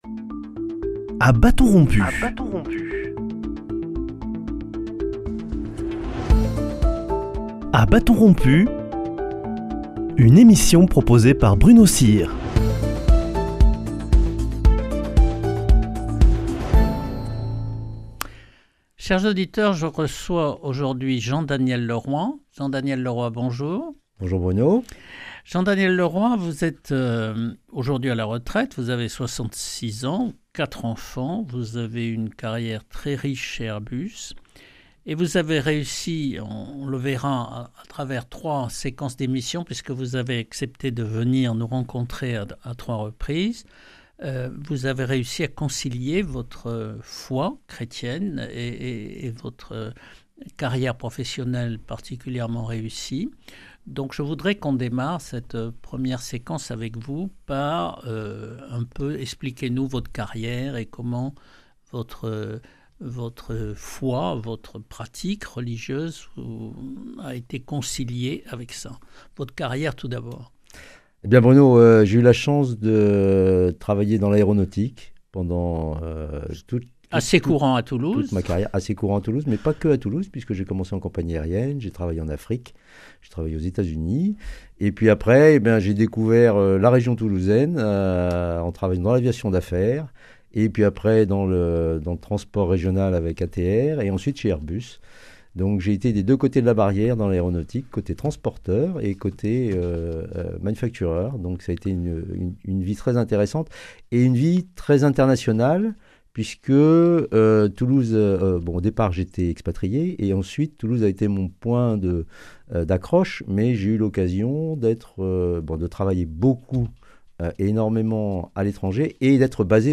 Dans le dernier entretien qu’il nous a accordé, il explique comment on peut soutenir cette association. Il nous parle aussi du Cambodge aujourd’hui et du rôle des ONG dans les pays en voie de développement.